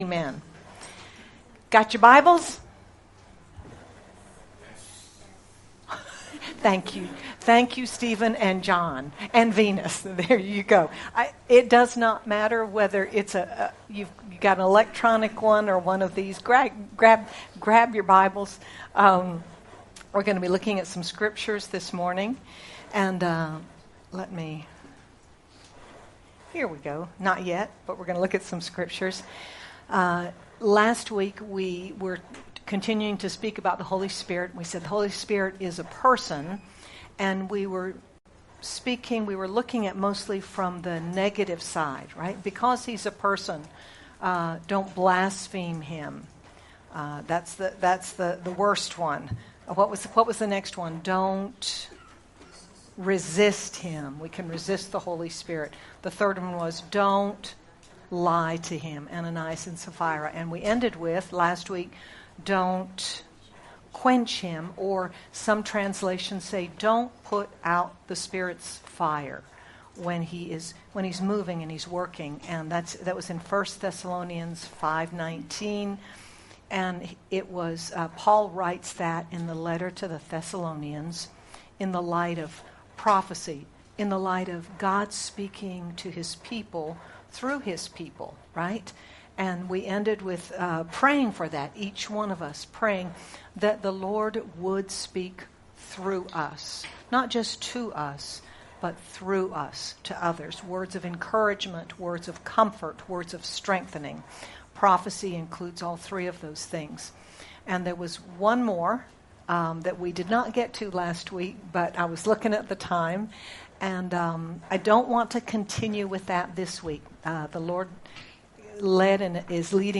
You also have a heavenly prayer partner—Someone who is always interceding for you. Sermon By